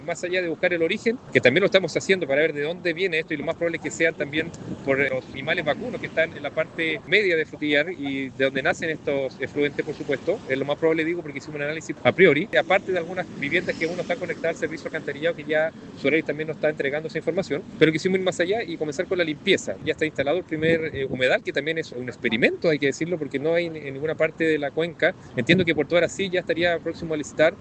Fue el alcalde Javier Arismendi quien señaló a La Radio que evaluarán el impacto de esto para analizar la oferta.